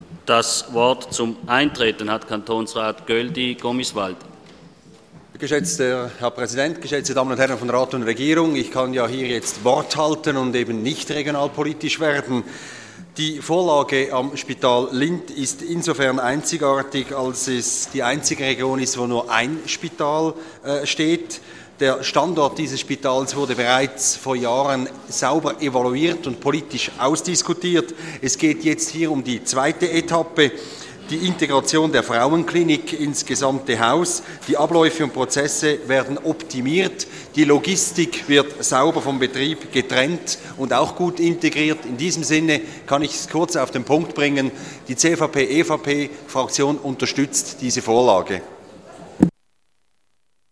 Session des Kantonsrates vom 26. Februar 2014, ausserordentliche Session